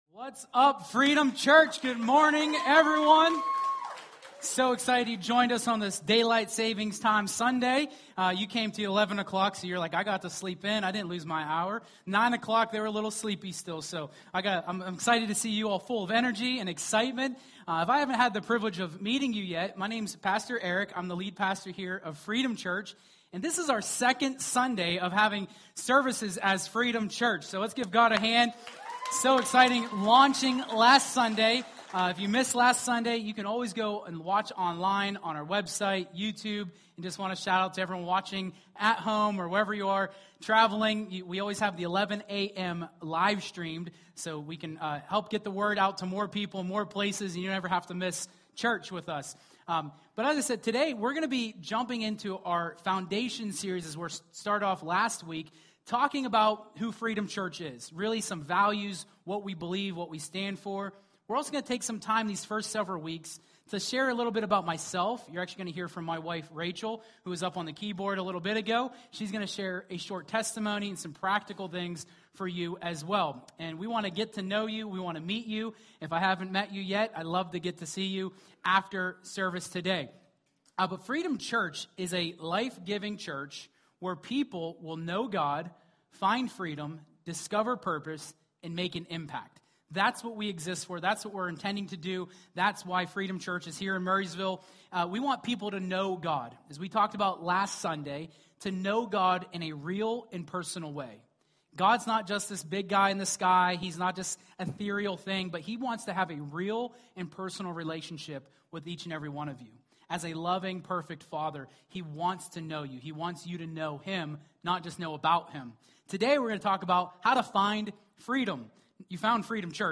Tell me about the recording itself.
2026 Freedom Church Launch Sunday Service This uplifting service explores themes of freedom in Christ